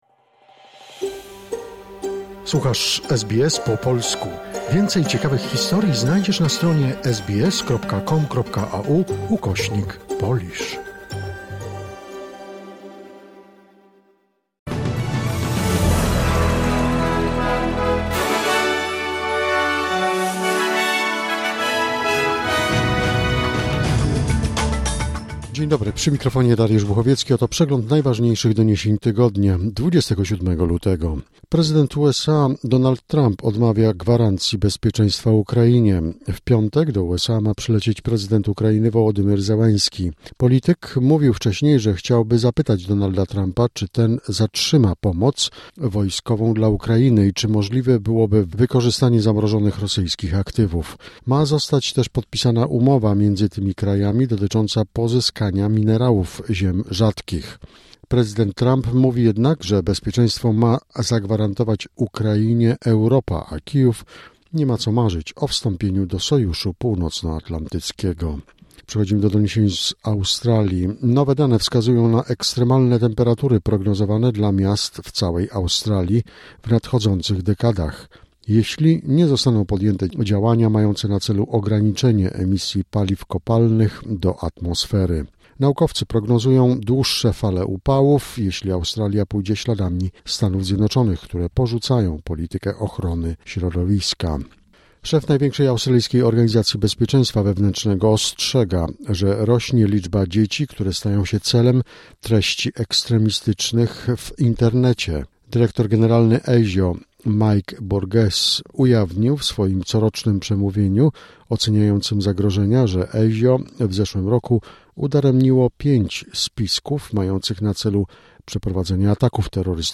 Wiadomości 27 lutego SBS Weekly Wrap